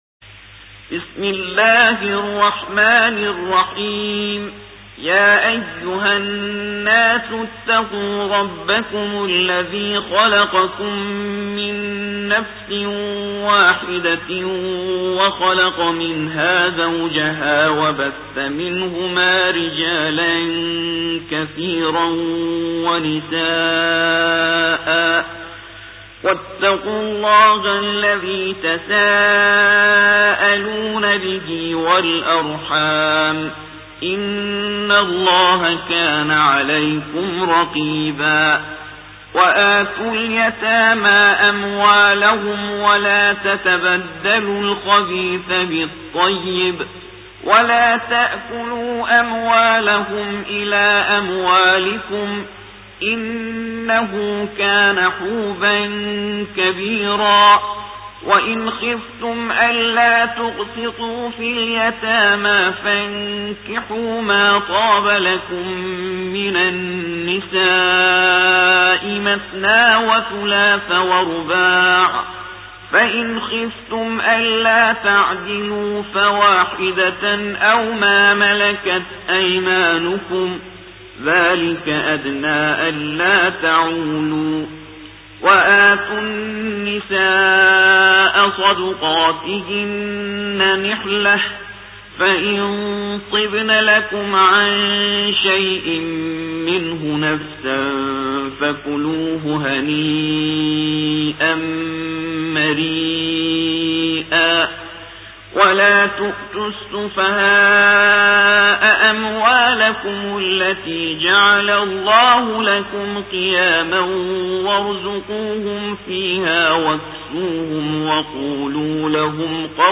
4. سورة النساء / القارئ